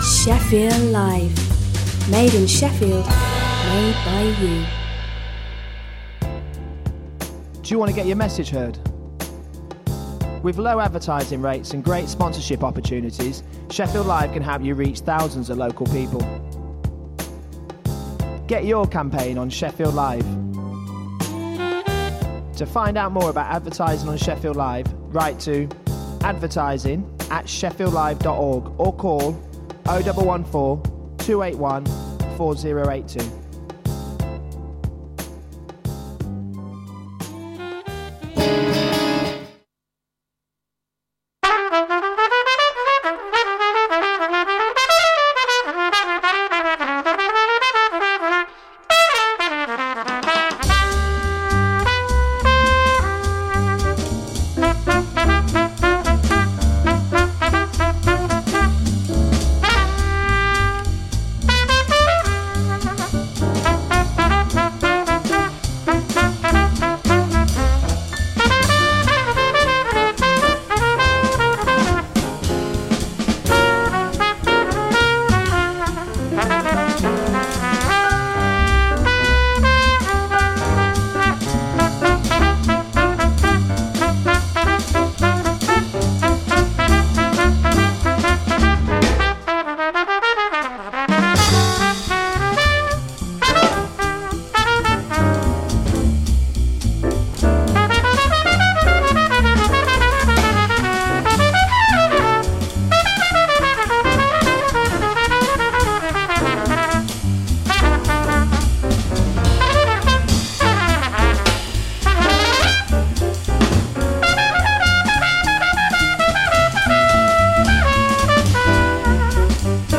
Film and theatre reviews plus swing classics.